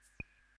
menu-popup.ogg